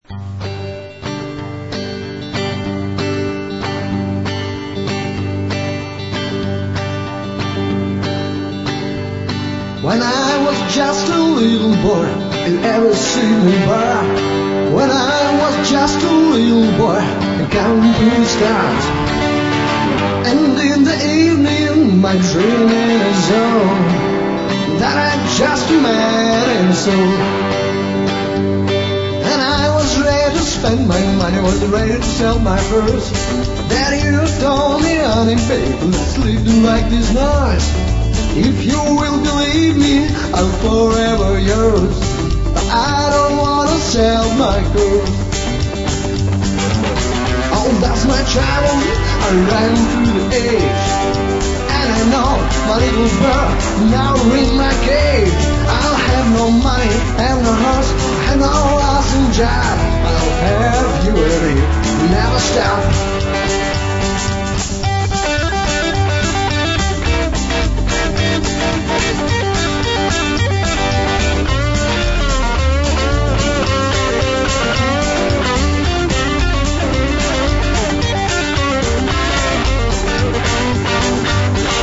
Душевное кантри для настоящих ковбоев про любимую лошадь...